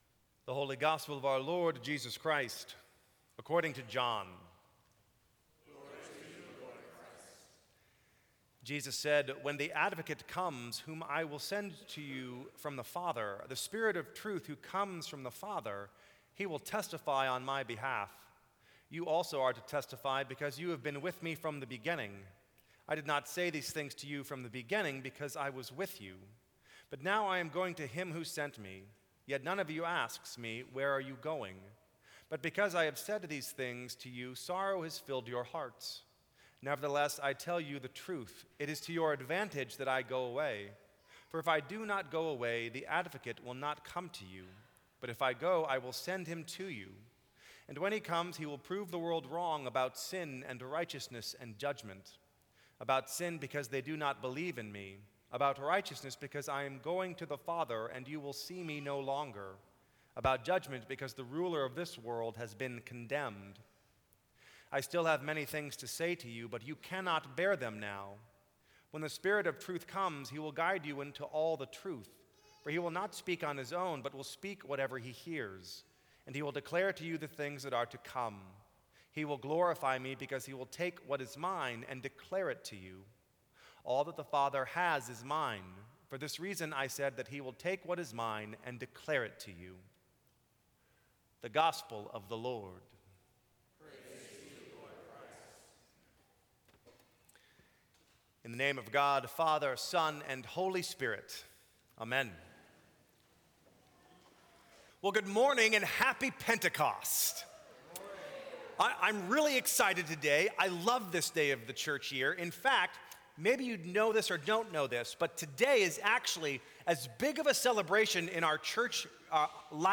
Sermons from St. Cross Episcopal Church Holy Spirit: Episcopal Style Sep 25 2015 | 00:11:36 1x 00:00 / 00:11:36 Subscribe Share Apple Podcasts Spotify Overcast RSS Feed Share Link Embed